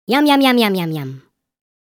Yum Yum Yum Sound Effect
Description: Yum yum yum sound effect. Bring humor and fun to your projects with this cartoon funny voice sound effect. Perfect for videos, animations, school projects, and video editing needing playful, silly audio.
Yum-yum-yum-sound-effect.mp3